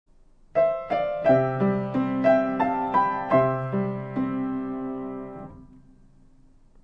ピアノ演奏
Tr5=(効果音)再会の音楽